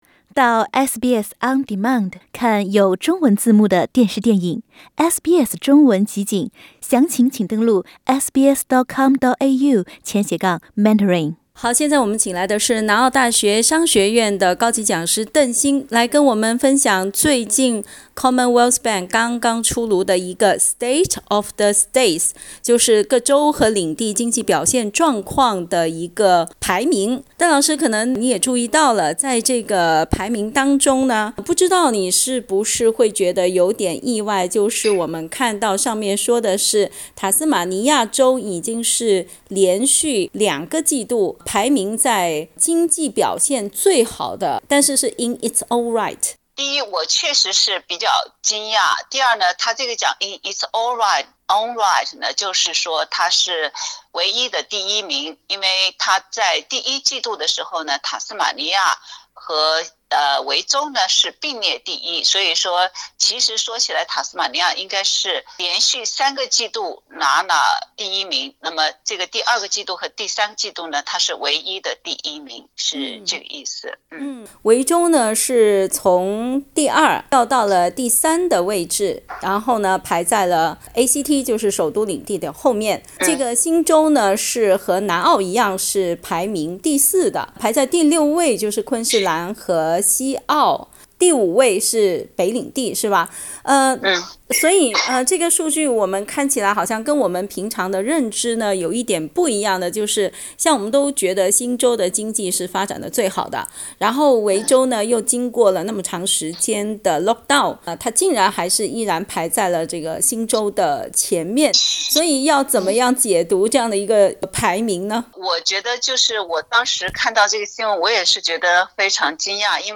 （本节目为嘉宾观点，请听采访。）